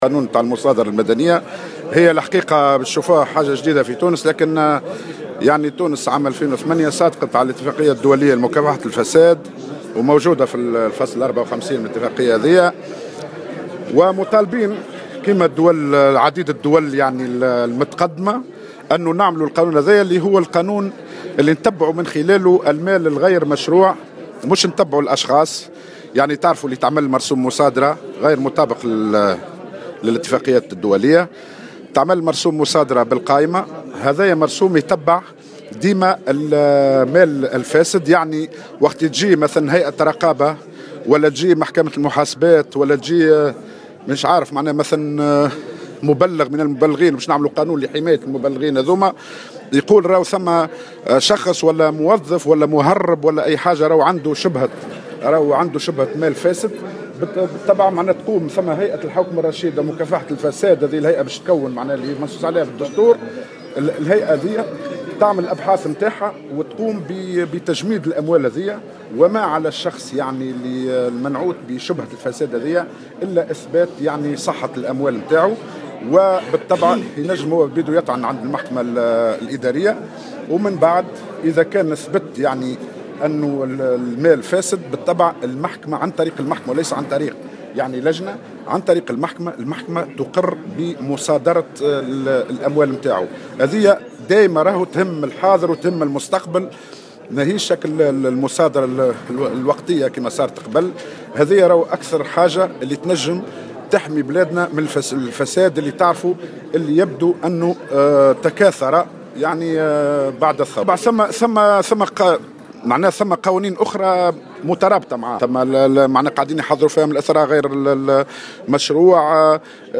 أكد وزير أملاك الدولة حاتم العشي في تصريح اعلامي على هامش ندوة عقدت بالعاصمة اليوم الخميس أن مشروع قانون المصادرة المدنية سيكون قانونا جديدا على تونس رغم مصادقة تونس سنة 2008 على الإتفاقية الدولية لمكافحة الفساد.